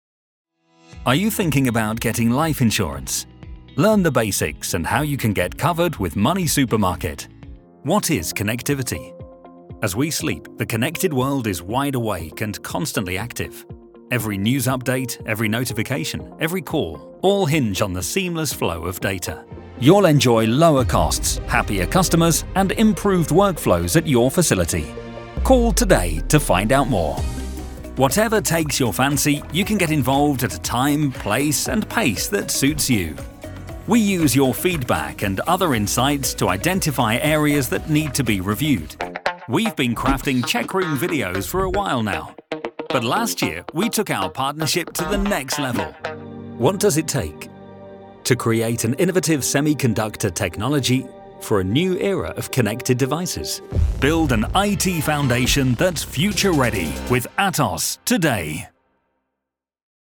Navex Voice Over Commercial Actor + Voice Over Jobs
My naturally dynamic, friendly, warm and conversational voice is perfect for any commercial project or narration and telling the story of your brand.